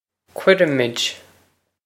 Pronunciation for how to say
kwere-ih-mwid
This is an approximate phonetic pronunciation of the phrase.